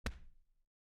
FlashlightFlick.mp3